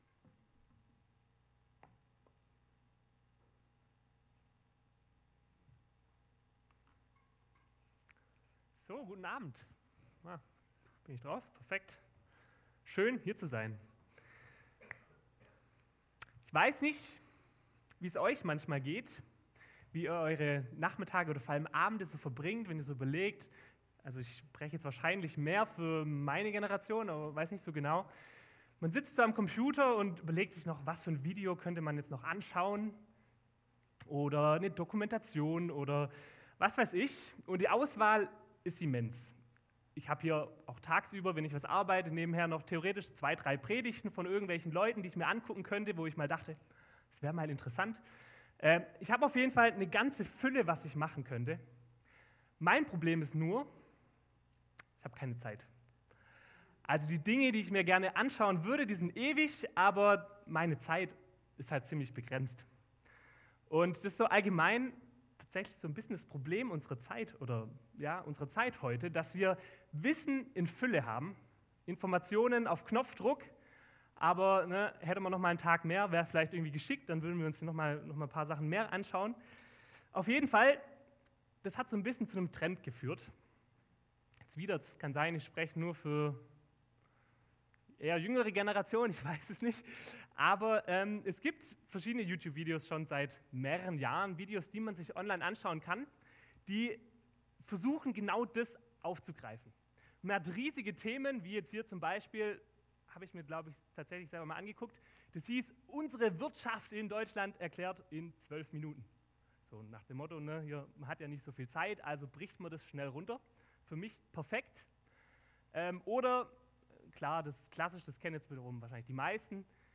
Gottesdienst am 02.06.2024